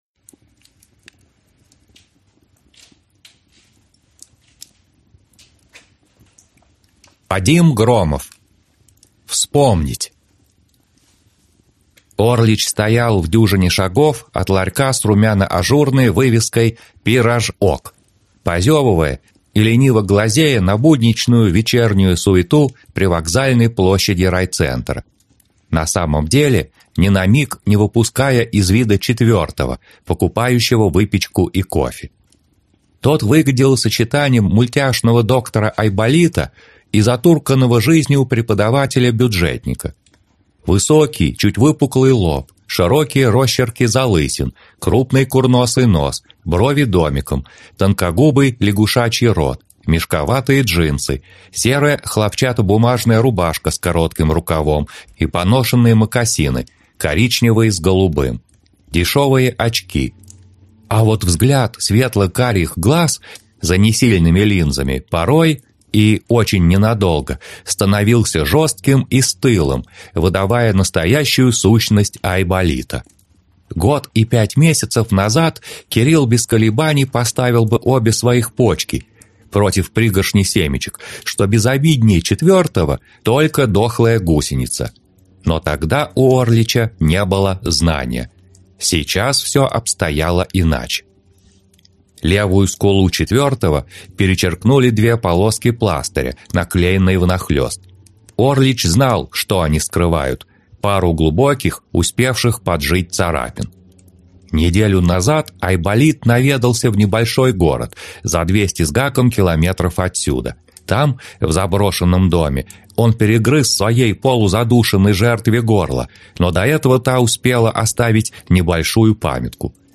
Аудиокнига Заброшенное кладбище | Библиотека аудиокниг